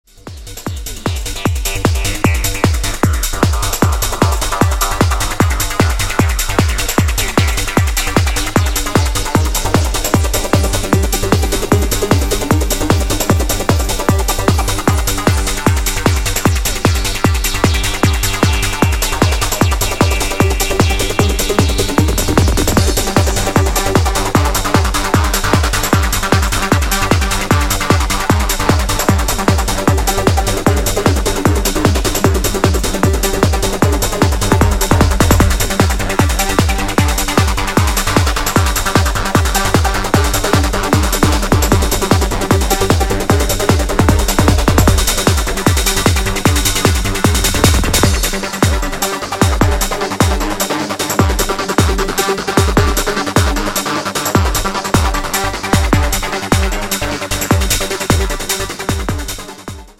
Style: Goa Trance , Downtempo